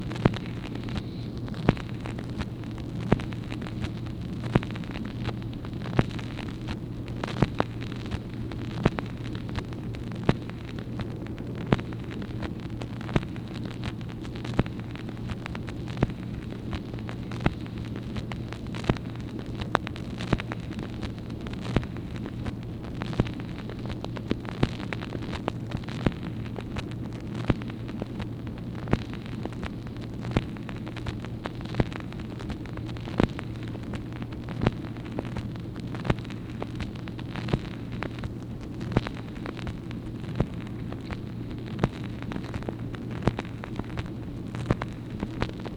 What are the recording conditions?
Secret White House Tapes | Lyndon B. Johnson Presidency